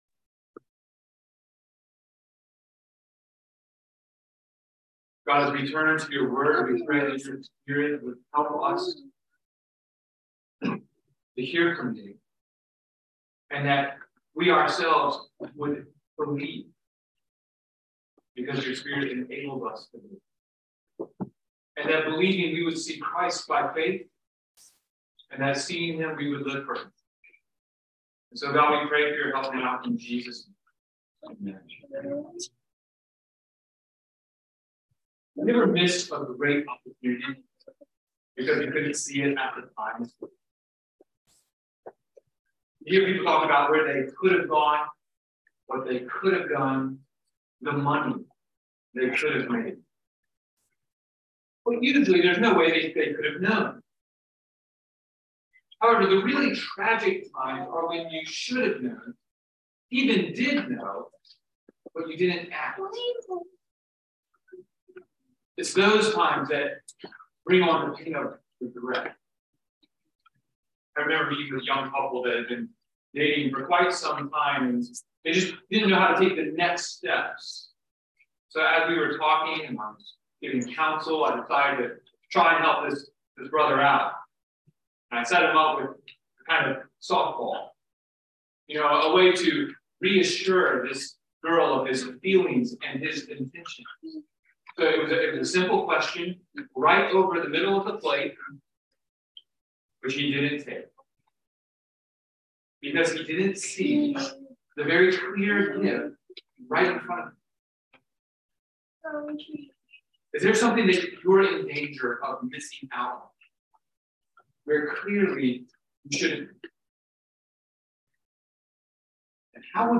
by Trinity Presbyterian Church | Nov 29, 2022 | Sermon